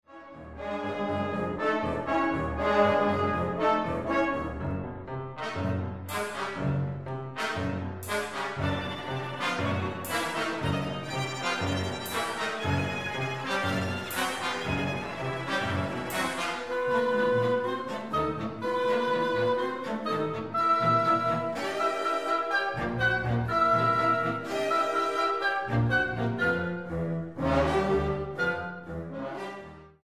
para conjunto de cámara